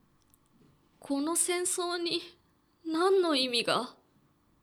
ボイス
中性